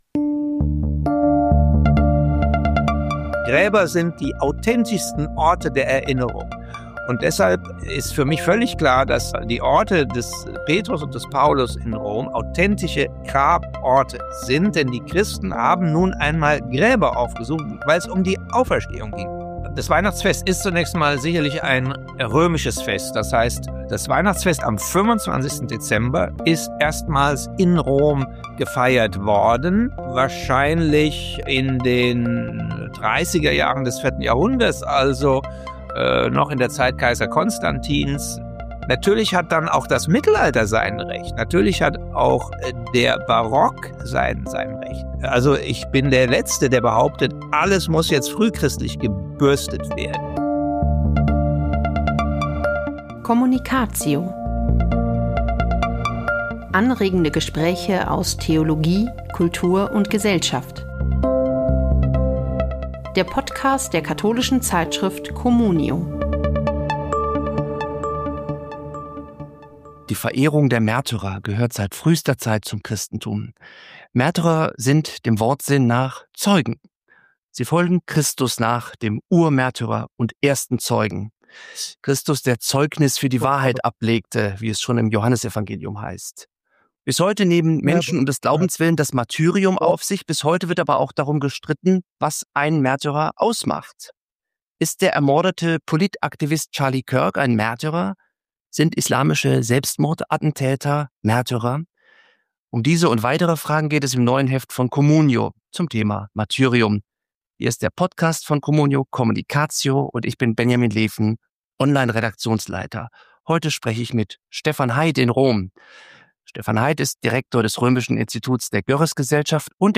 Der Podcast zum neuen Heft von COMMUNIO zum Thema "Martyrium".